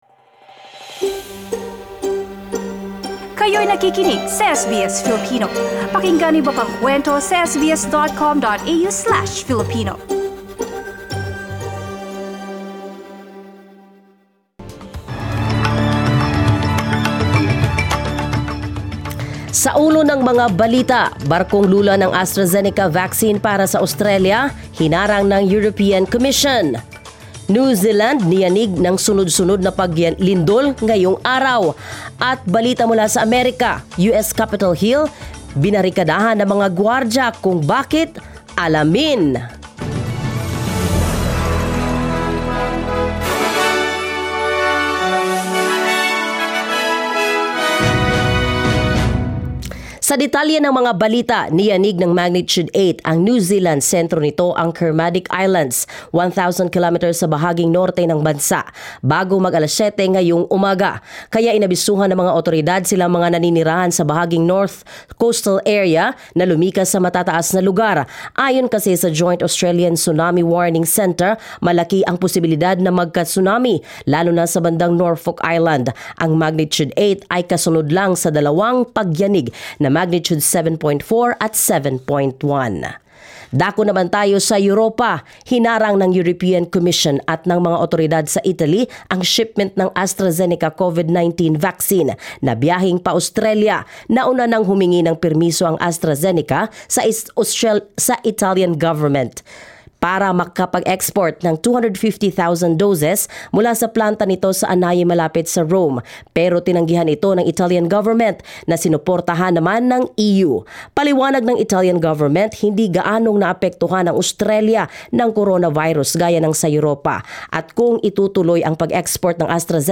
SBS News in Filipino, Friday 5 March